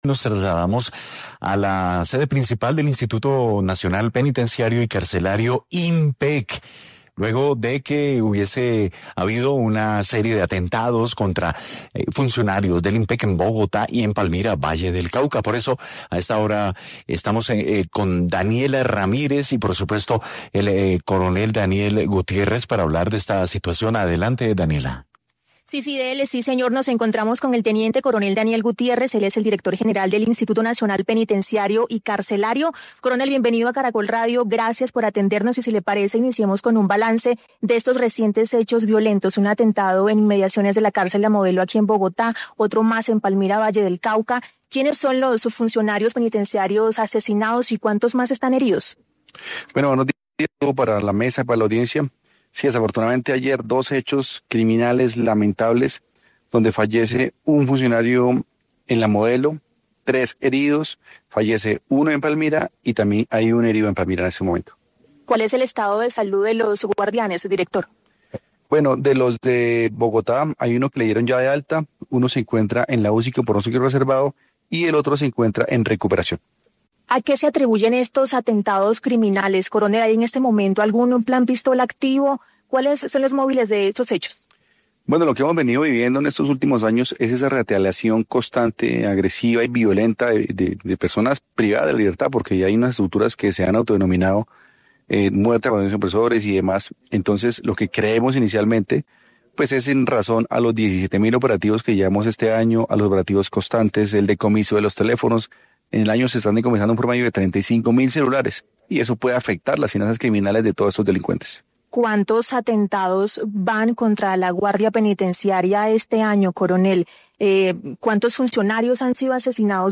En entrevista con Caracol Radio, el teniente coronel Daniel Gutiérrez, director del INPEC, entregó detalles de la situación.
En el noticiero de mediodía de Caracol Radio, el teniente coronel Daniel Gutiérrez, director del INPEC, entregó un balance de los recientes ataques sicariales: